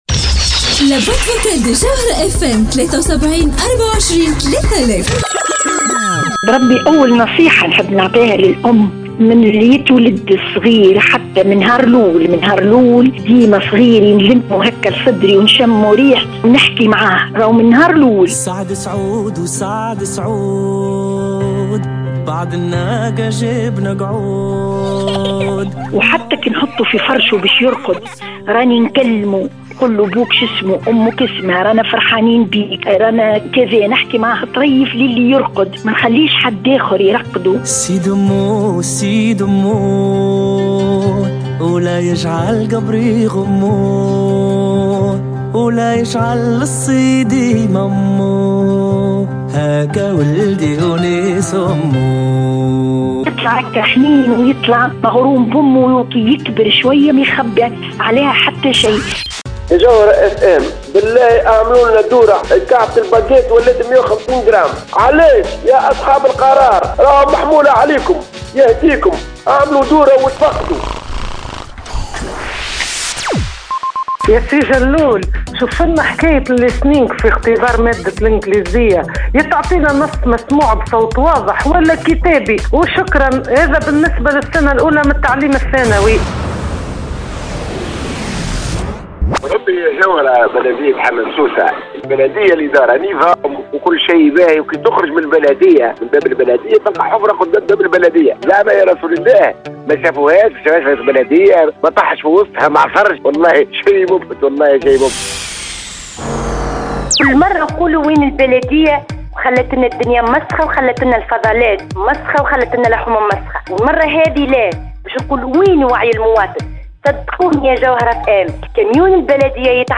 مستمعة : بربي حنّو على صغاركم وحكيو معاهم من اللي هوما صغار